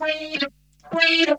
VOC WEEDUP.wav